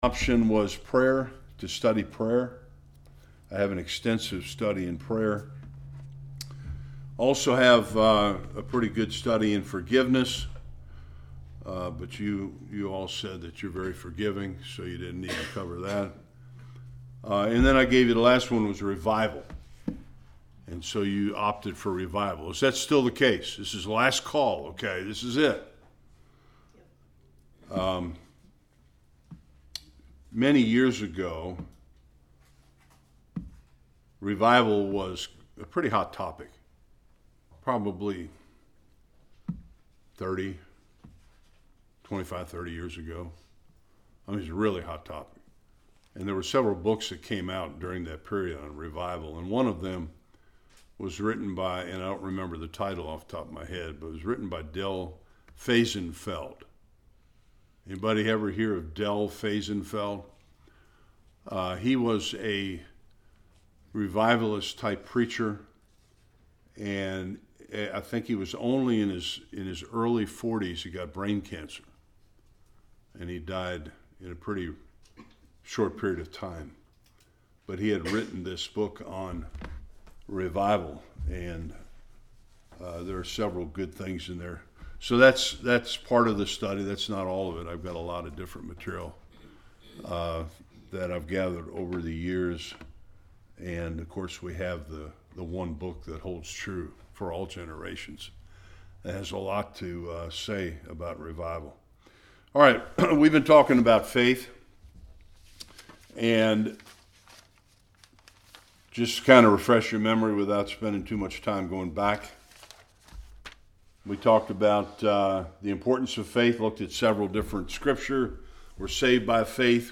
Various Passages Service Type: Bible Study The last 9 of 12 results of faith.